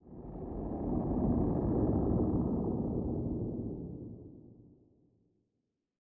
Minecraft Version Minecraft Version 1.21.5 Latest Release | Latest Snapshot 1.21.5 / assets / minecraft / sounds / ambient / nether / warped_forest / here3.ogg Compare With Compare With Latest Release | Latest Snapshot